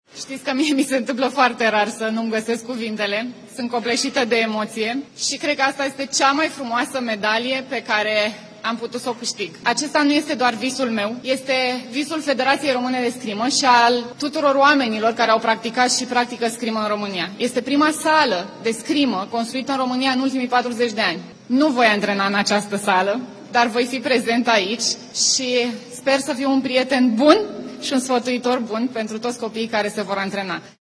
Ana Maria Brânză, fostă campioană olimpică la spadă: „Cred că aceasta este cea mai frumoasă medalie pe care am putut s-o câștig”